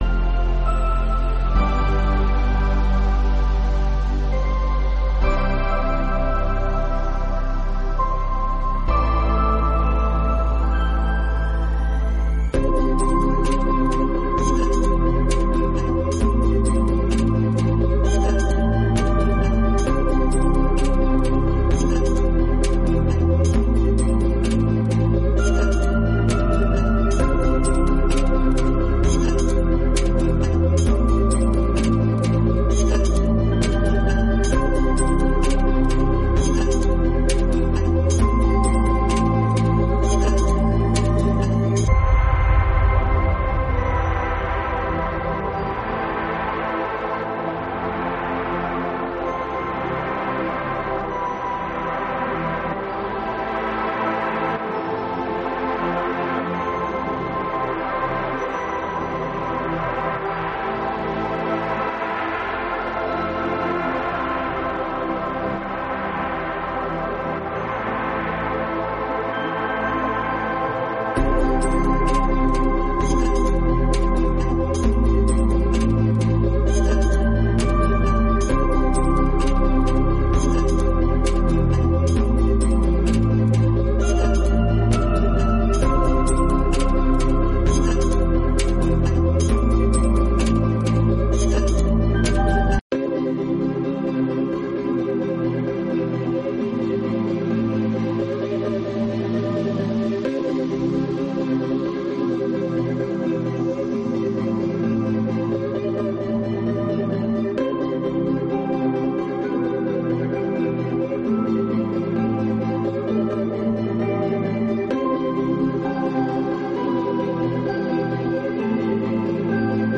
3ª Sessão Extraordinária de 2021